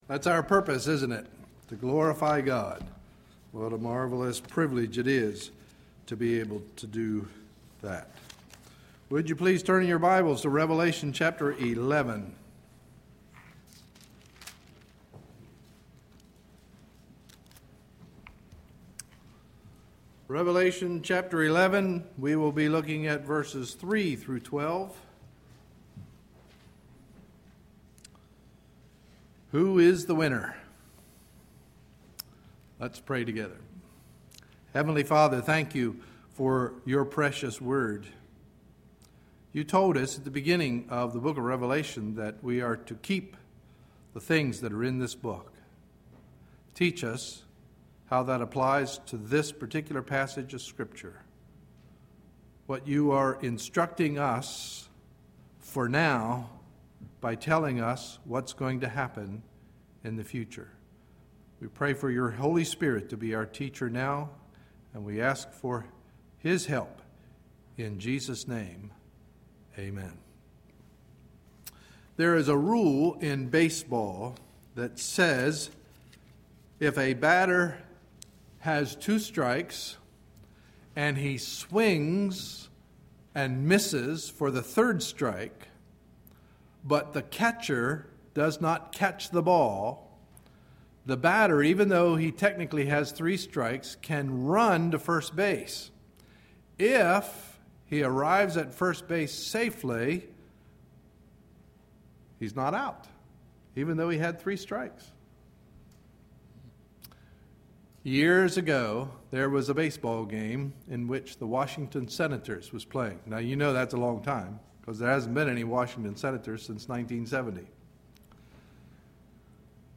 Sunday, September 4, 2011 – Morning Message